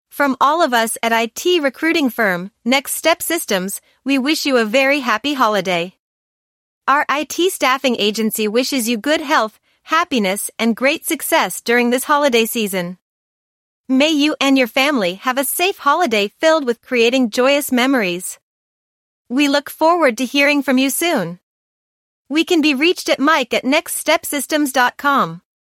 A Happy Holidays Message from IT Recruiting Firm, Next Step Systems Using Artificial Intelligence (AI)
Please take a moment to listen to a Happy Holiday audio message from our IT recruiting firm, Next Step Systems generated by Artificial Intelligence (AI). Our IT staffing agency wishes you good health, happiness and success during this holiday season.